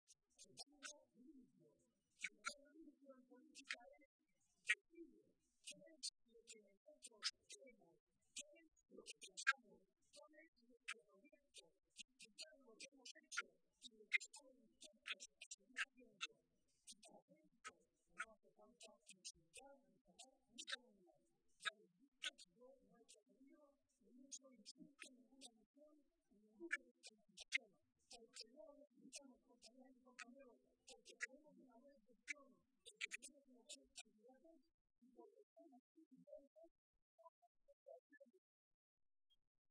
El presidente José María Barreda asistía este domingo a la tradicional comida de Navidad del PSOE de Toledo, en la que participaron unos 1.600 militantes y simpatizantes de toda la provincia.